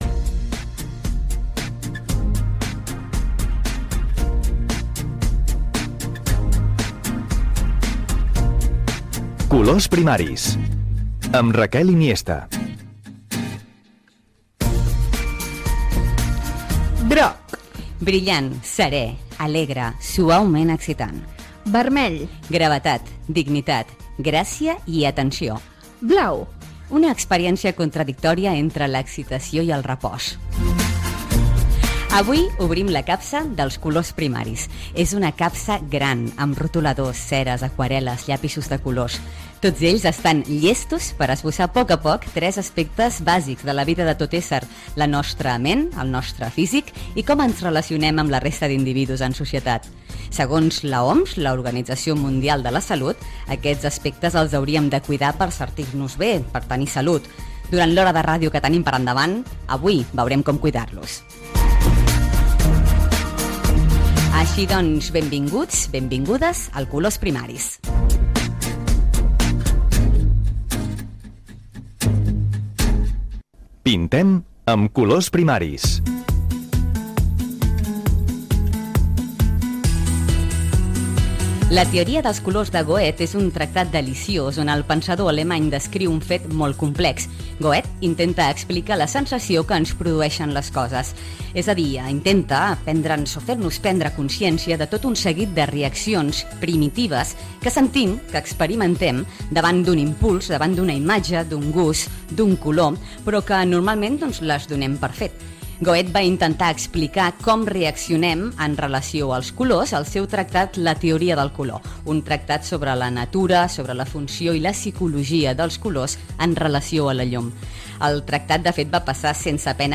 Careta i presentació del primer programa amb l'explicació de la gamma de colors primaris i el sumari
Entreteniment